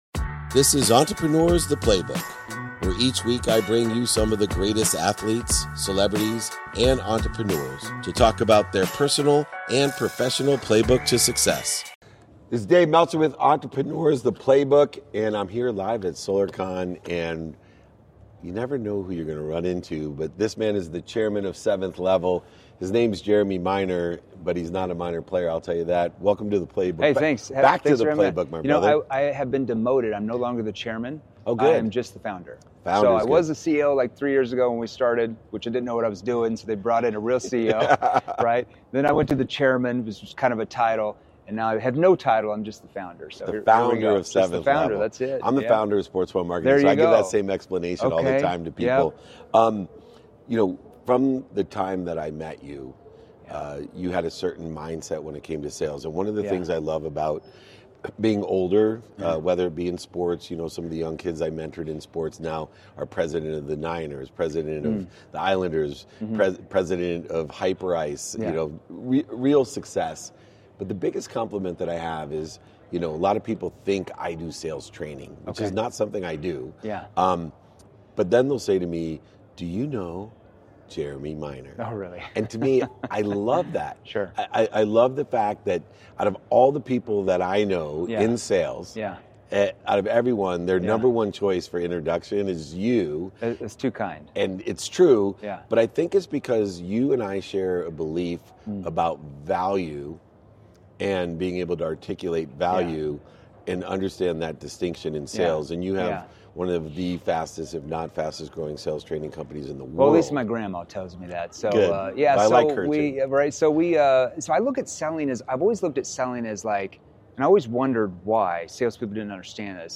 live from Solar Con.